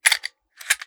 9mm Micro Pistol - Cocking Slide 003.wav